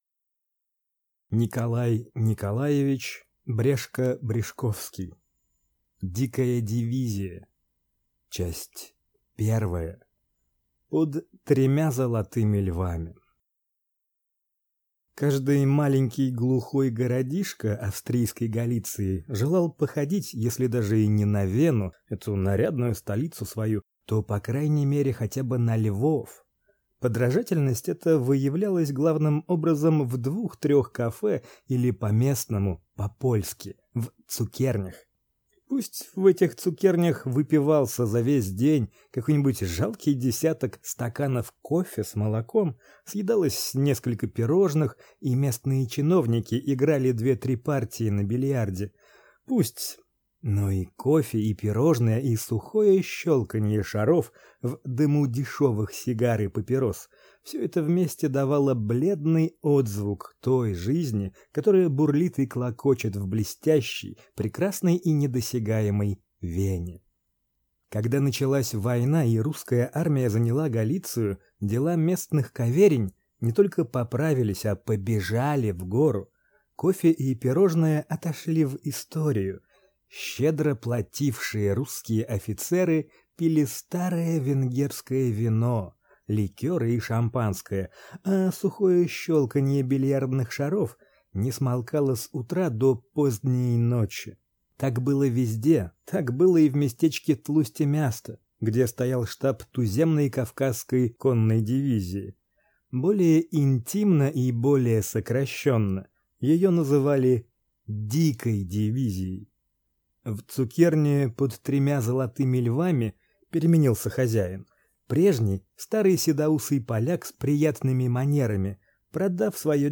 Аудиокнига Дикая дивизия | Библиотека аудиокниг